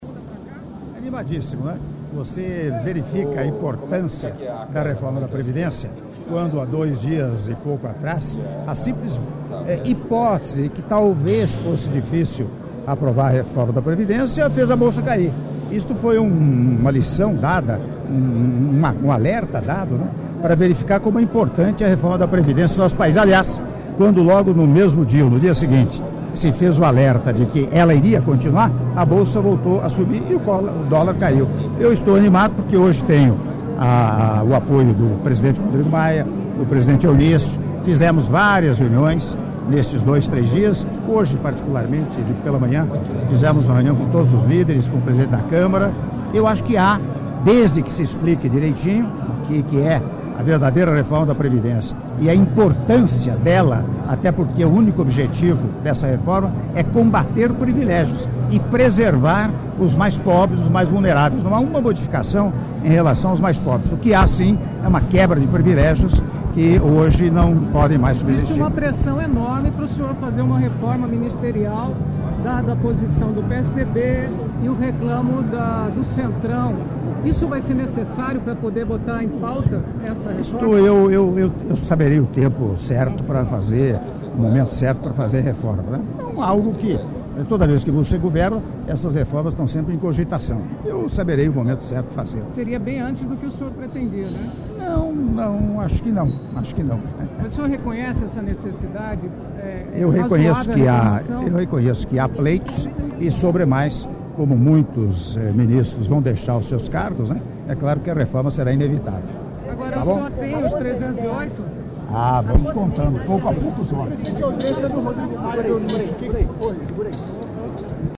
Áudio da entrevista coletiva concedida pelo Presidente da República, Michel Temer, cerimônia de Lançamento do Programa Avançar: iniciativa do Governo Federal para a conclusão de obras até 2018 - Brasília/DF (1min58s)